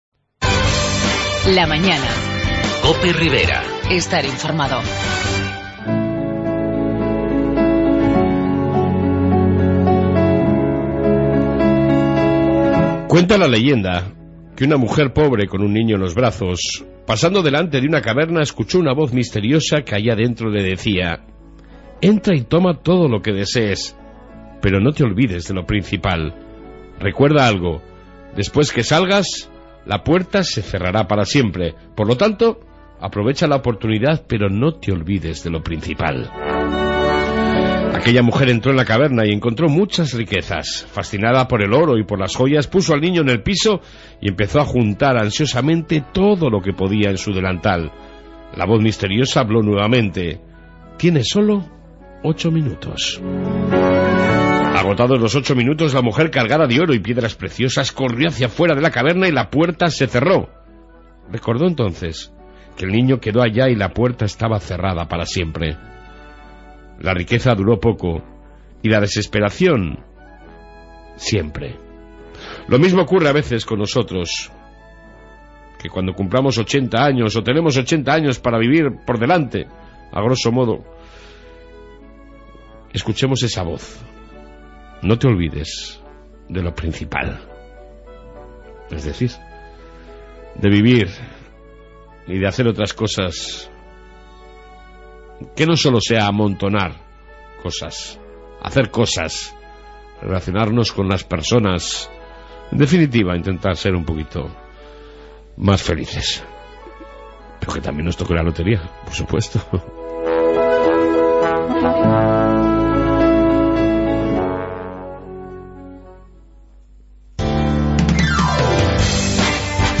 AUDIO: En esta 1ª parte Reflexión diaria, Informe policñia municipal, Noticias Riberas y entrevista